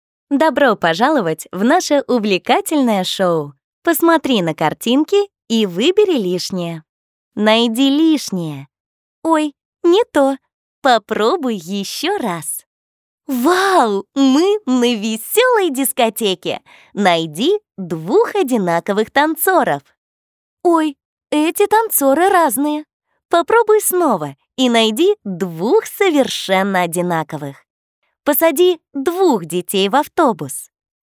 Commercieel, Jong, Natuurlijk, Vriendelijk, Zakelijk
E-learning
- native russian speaker without region accent